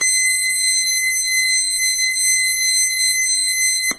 timerEnd.wav